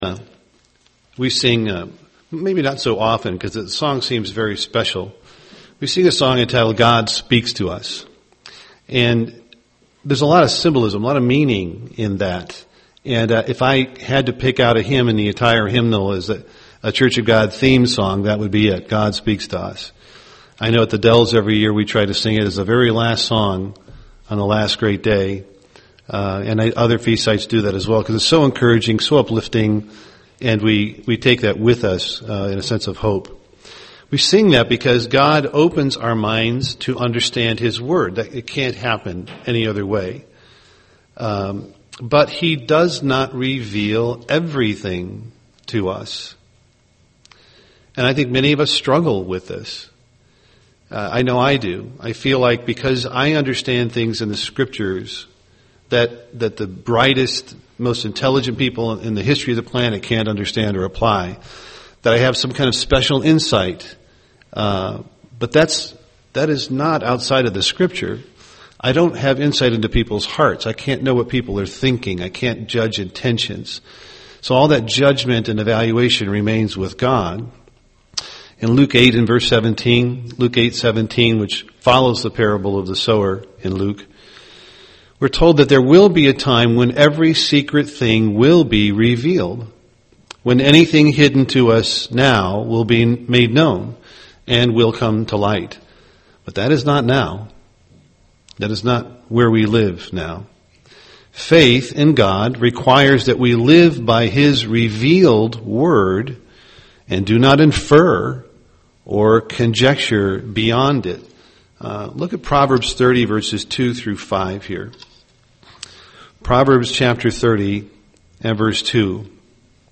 UCG Sermon judging speculation Studying the bible?